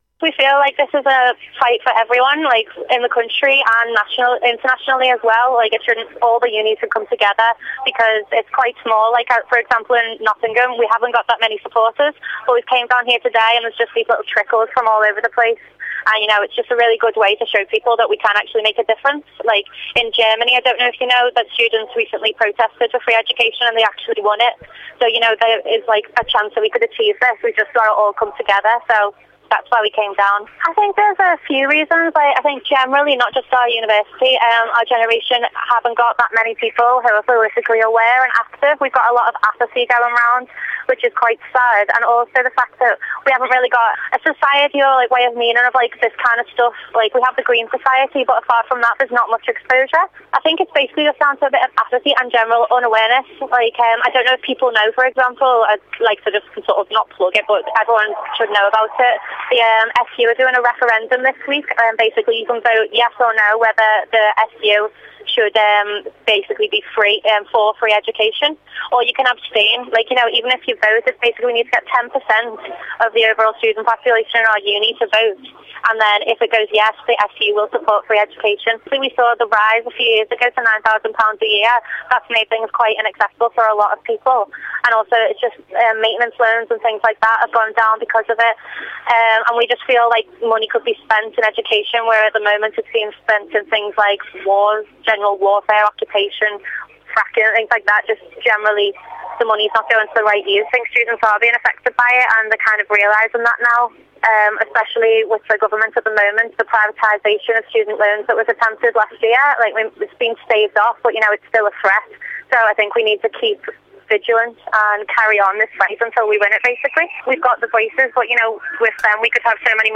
On the 19th November, thousands of students protested in London to campaign for the right for University education to be free. We spoke to one of the protesters at the March to find out why she was protesting - as well as catching up with your views around campus of student protests in general.